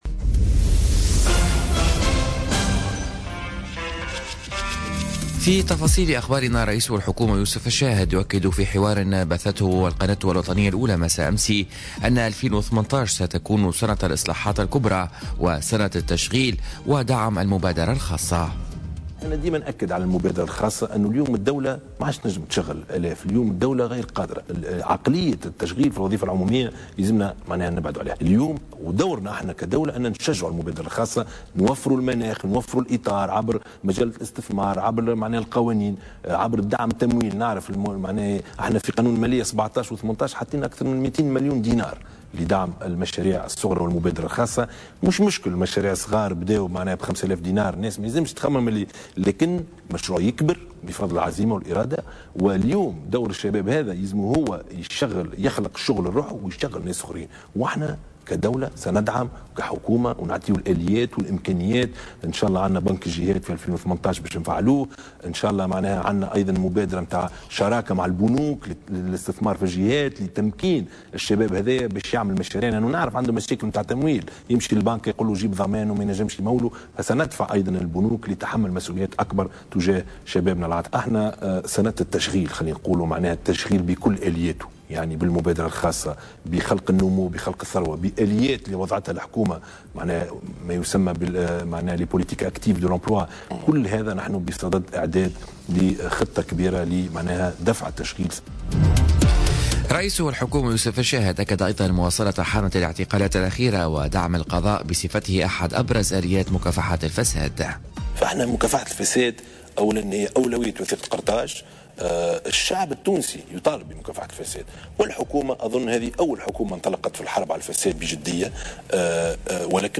نشرة أخبار السابعة صباحا ليوم الثلاثاء 02 جانفي 2018